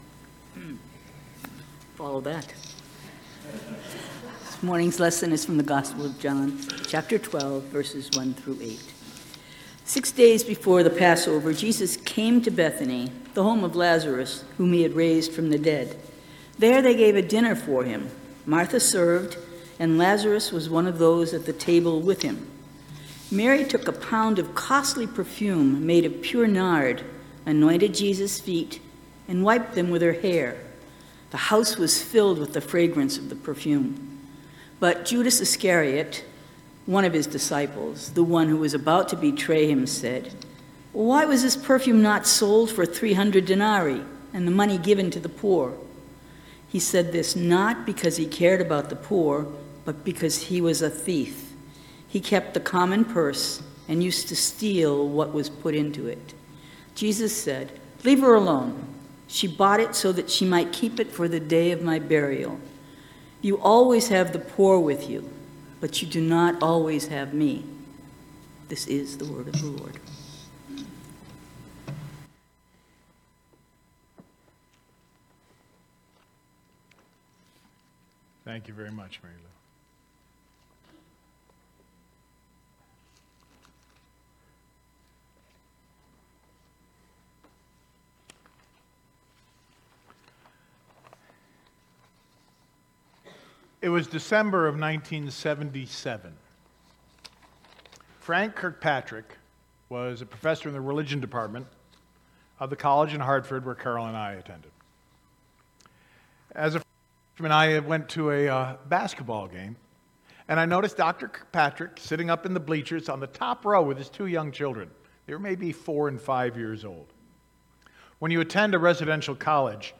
Scripture-Reading-and-Sermon-Sept.-3-2023-1.mp3